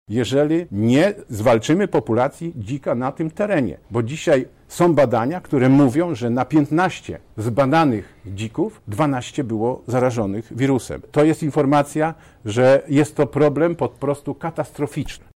Marszałek województwa chce płacić za każdego padłego dzika. Afrykański Pomór Świń coraz szybciej rozprzestrzenia się na terenie Lubelszczyzny – mówi Sławomir Sosnowski, Marszałek Województwa Lubelskiego: